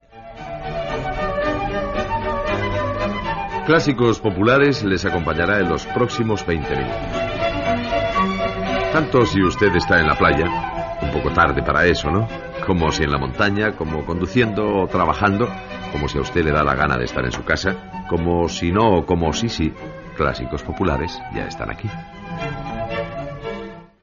Presentació inicial del programa
Musical